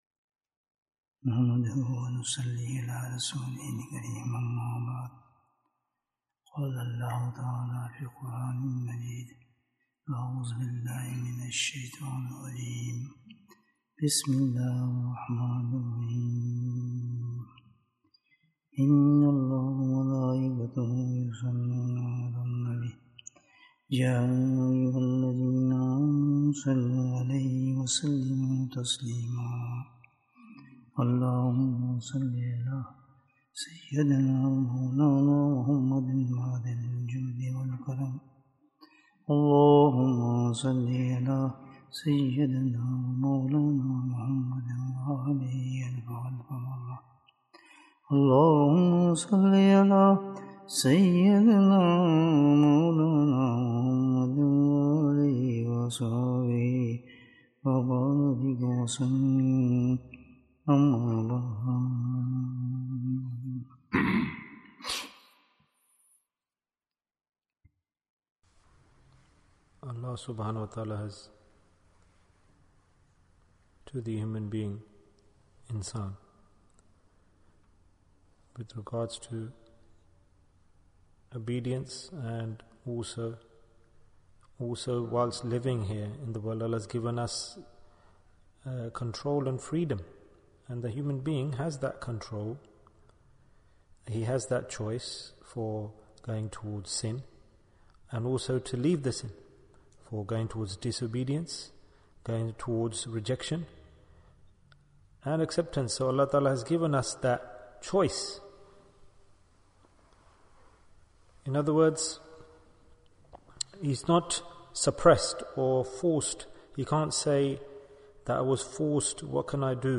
Why Are We Weak in Good Deeds? And It's Cure Bayan, 56 minutes16th February, 2023